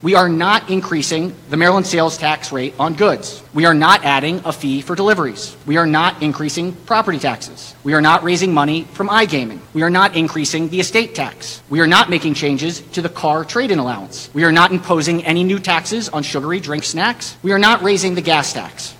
Democratic leaders held a press conference Thursday putting forth a new budget framework as the Maryland General Assembly works to overcome a multi-billion dollar deficit.
Senate President Bill Ferguson reviewed what taxes are NOT happening…